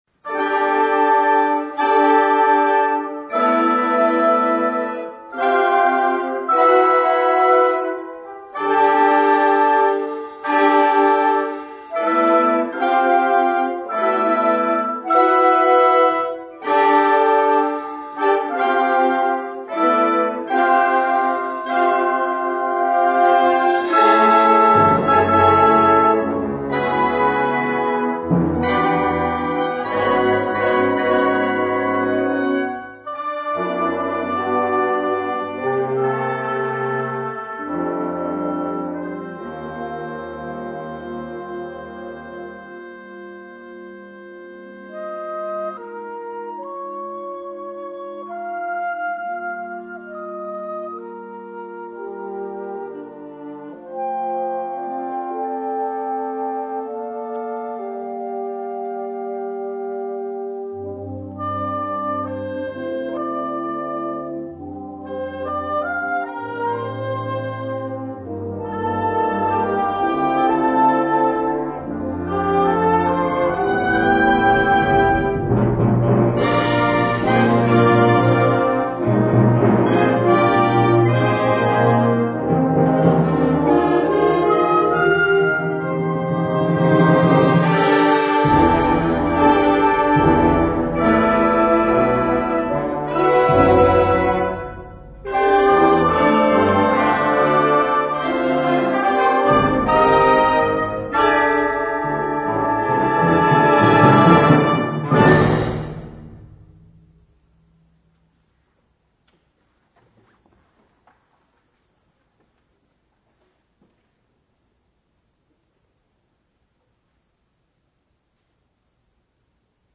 輸入吹奏楽オリジナル作品